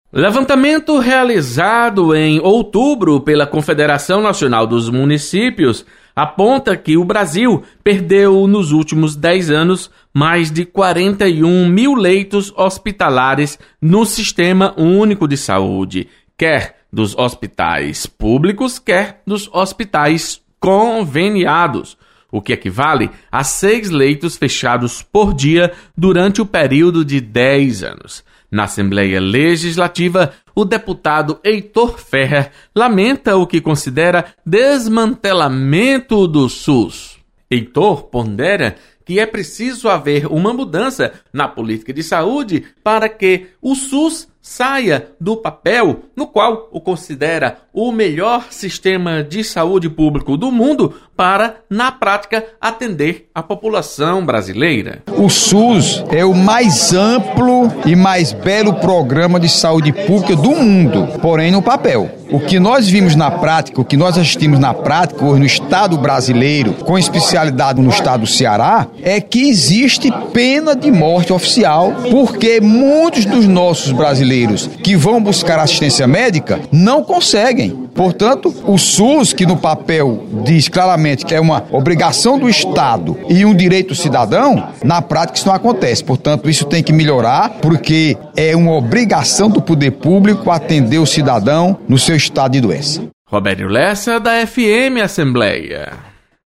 Deputado Heitor Férrer defende revitalização do SUS. Repórter